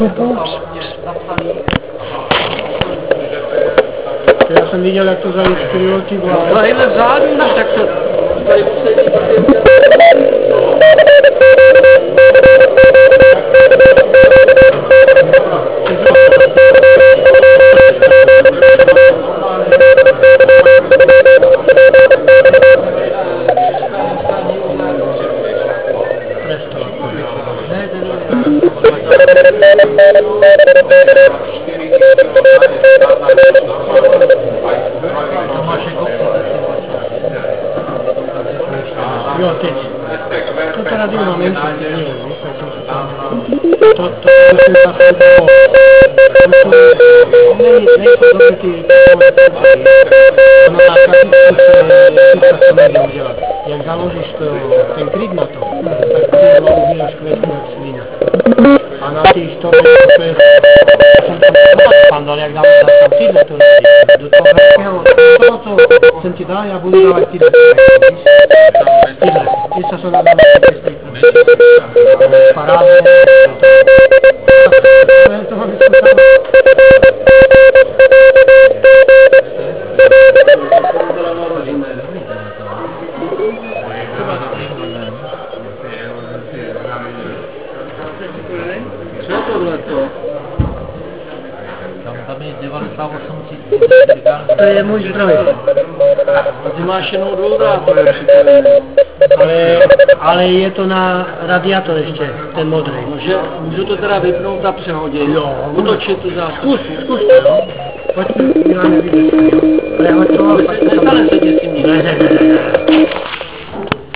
Zde pozor, vše co zde uslyšíte jsou "panelové diskuse" a občas padne i nějaké slovíčko..HI. Např. jsem měl záznam příjmu LIBRY 80, ale tam těch slovíček padlo více a tak to tady nenajdete HI.
Ocean 2005a zvuk (*.wav 460 KB)